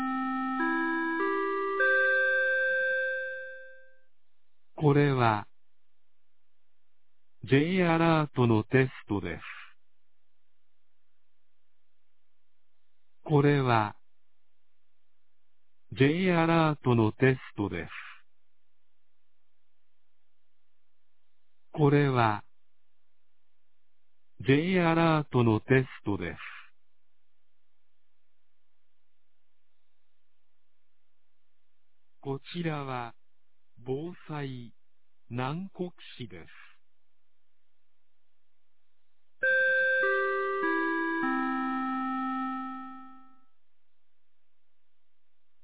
2023年02月15日 11時01分に、南国市より放送がありました。